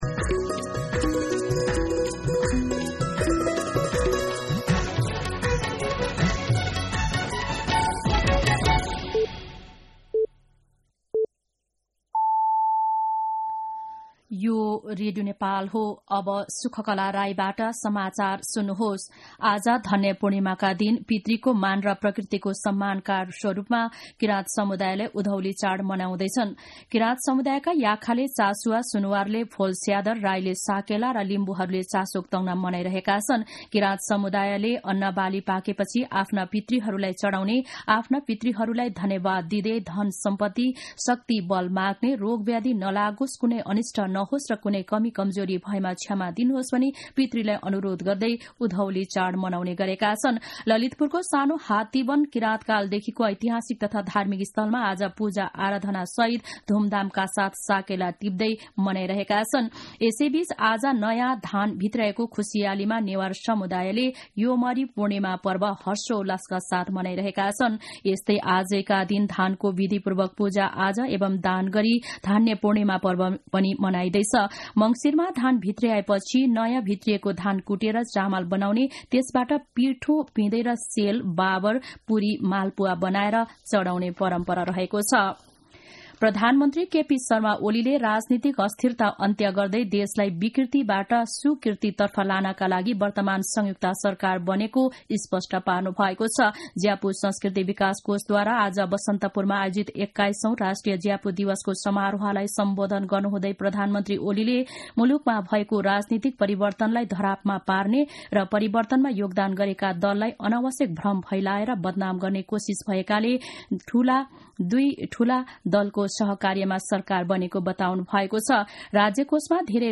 दिउँसो ४ बजेको नेपाली समाचार : १ पुष , २०८१
4-pm-Nepali-News-2.mp3